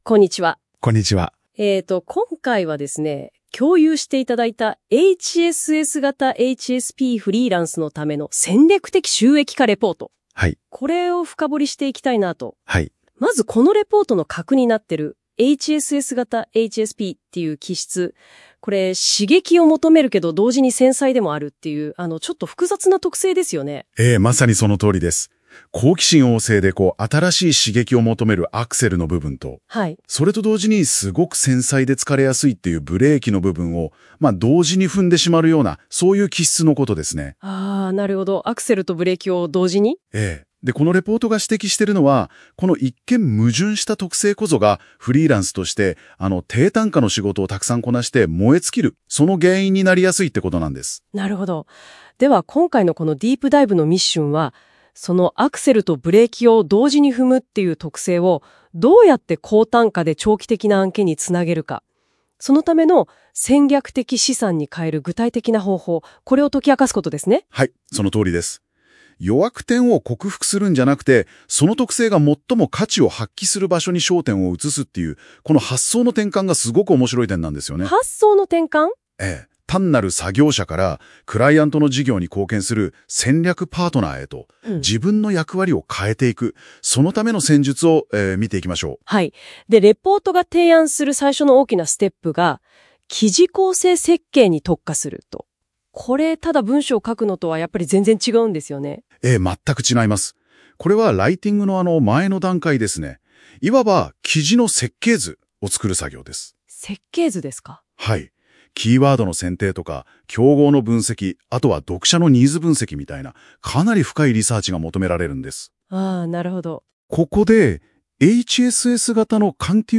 【音声解説①】HSS型HSPが疲弊せず稼ぐ戦略的収益化術